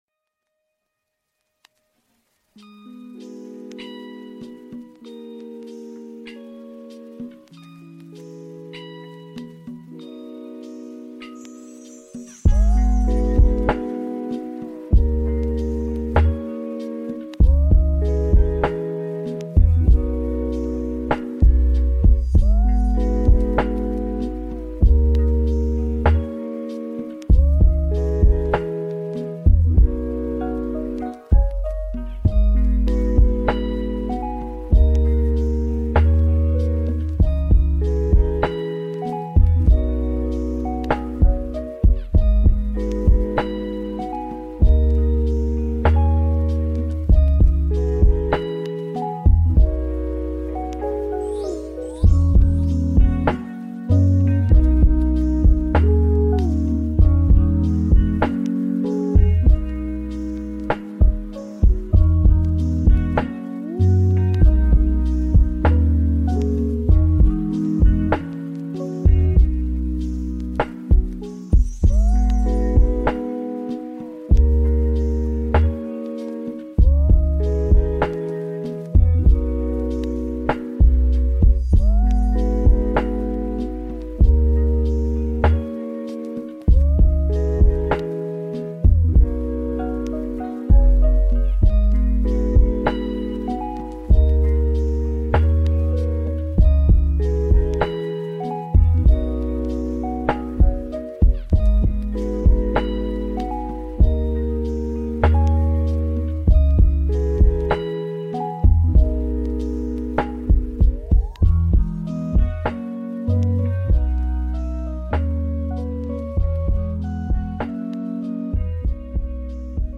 Pluie et Feu : Ambiance Douce